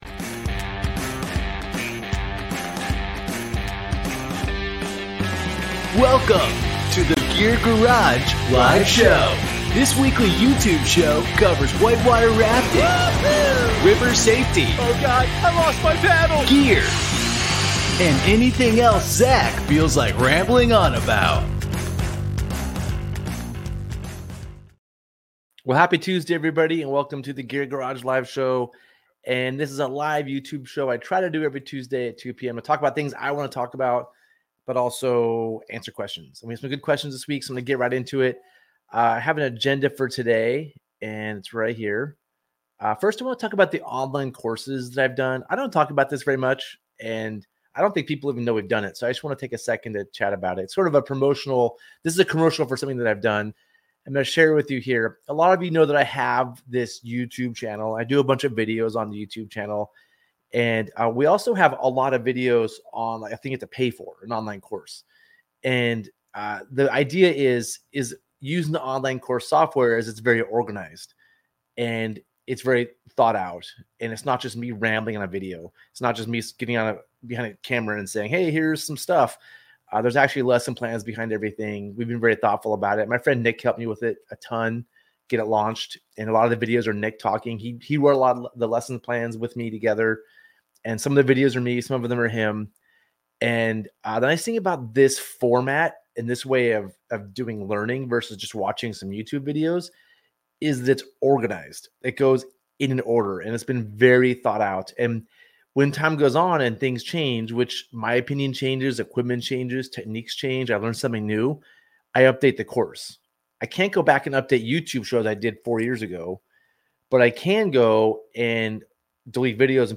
This podcast is the audio version of the Gear Garage Live Show, where we answer submitted questions and talk all things whitewater.